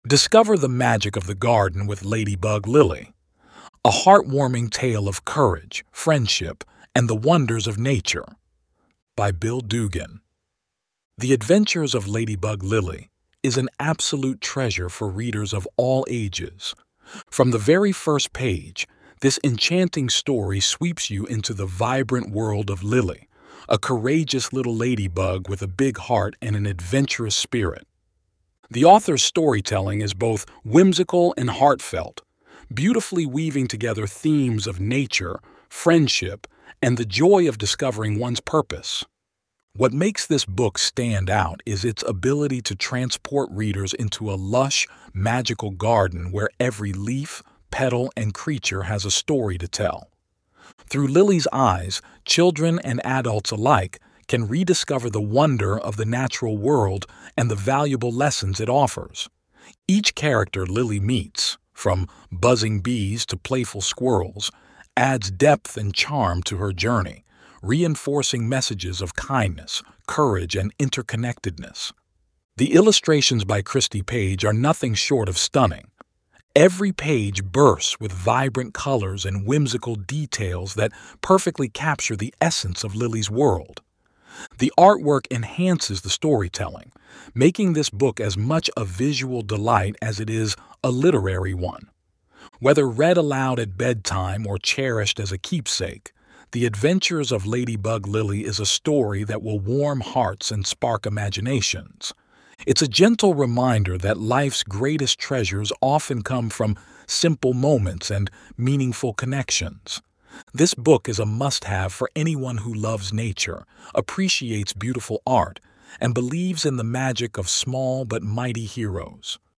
Product-Review-for-Adventures-of-Ladybug-Lily-StoryBook.mp3